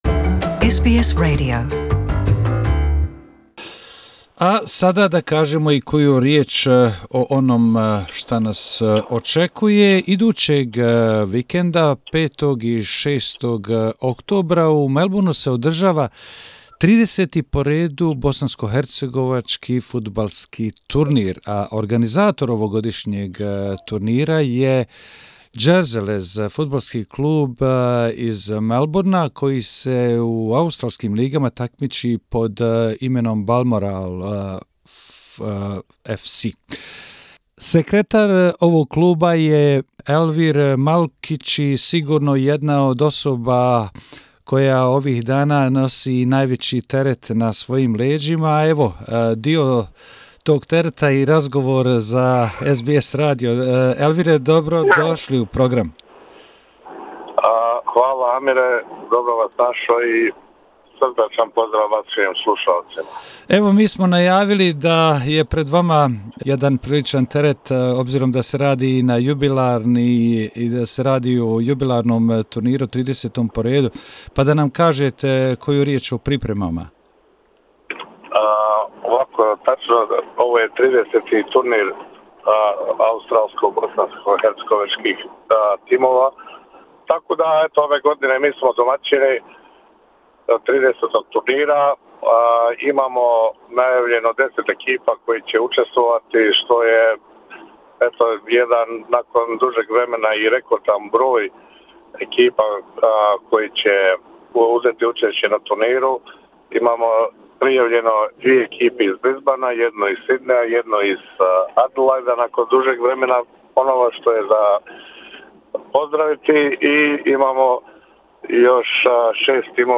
gostujući u našem programu govorio o pripremama, učesnicima i satnici turnira